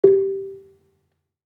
HSS-Gamelan-1 / Gambang
Gambang-G3-f.wav